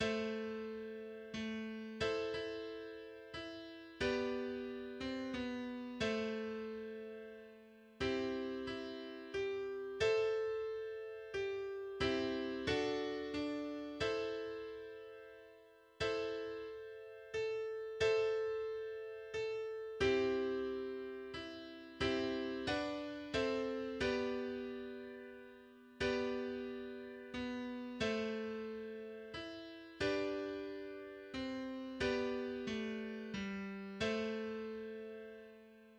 Text & Melodie Volkslied